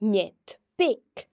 The slender N sound is made by pressing the tongue against the palate, and is made when the N occurs next to e or i in a word.